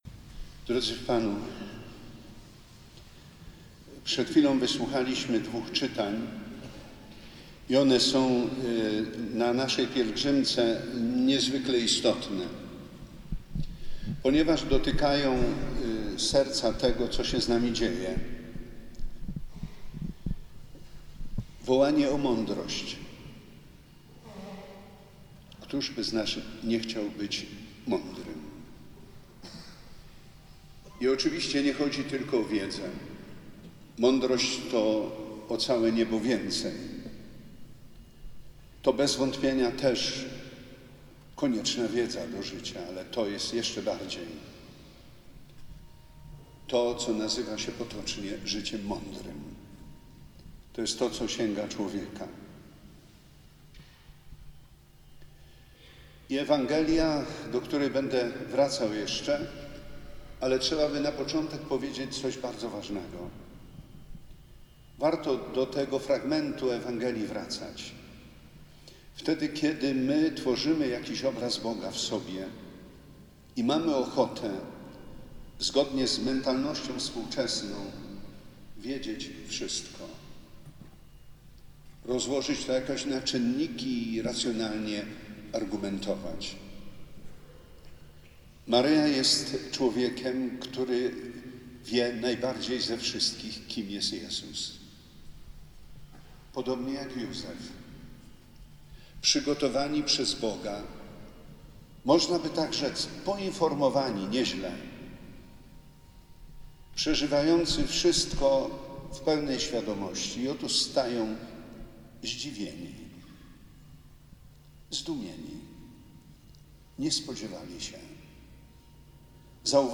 33. Pielgrzymka Maturzystów odbyła się 23 marca. Bp Edward Dajczak przewodniczył Mszy św. w jasnogórskiej bazylice.
Bp Edward Dajczak do maturzystów na Jasnej Górze - homilia
Zapraszamy do wysłuchania homilii bp. Edwarda Dajczaka: